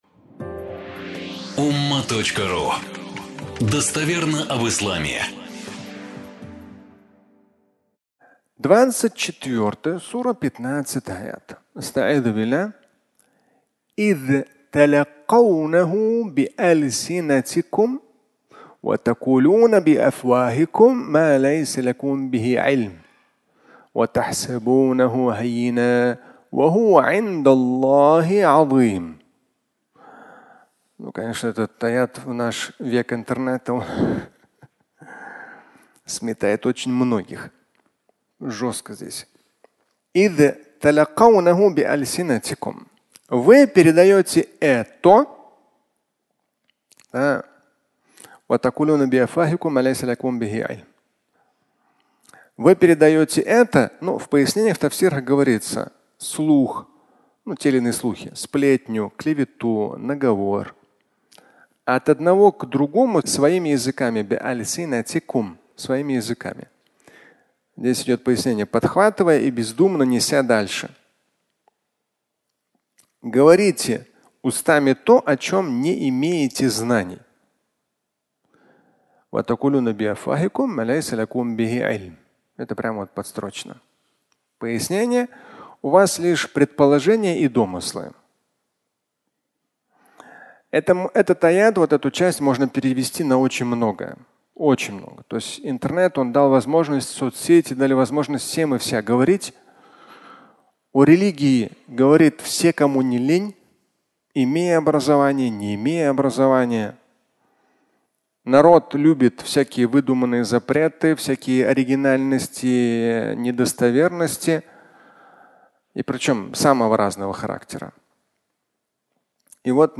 Аудио статья
Пятничная проповедь